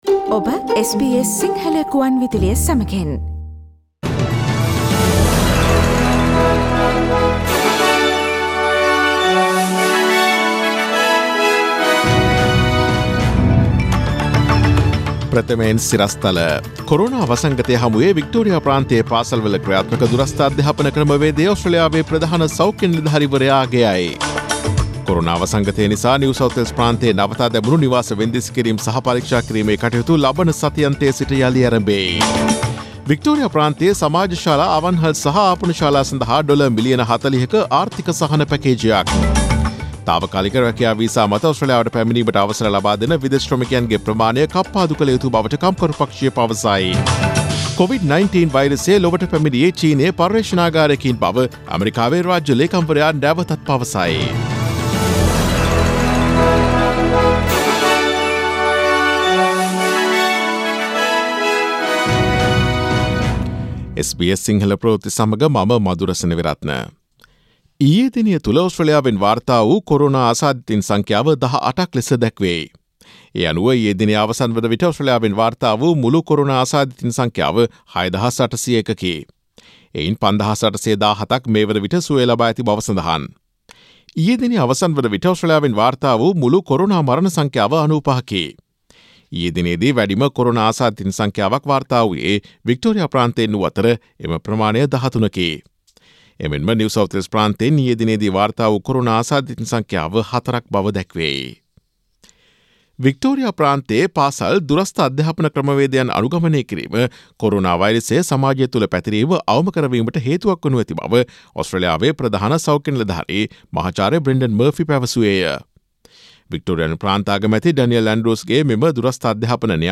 Daily News bulletin of SBS Sinhala Service: Monday 04 May 2020
Today’s news bulletin of SBS Sinhala Radio – Monday 04 May 2020 Listen to SBS Sinhala Radio on Monday, Tuesday, Thursday and Friday between 11 am to 12 noon